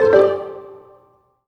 happy_collect_item_08.wav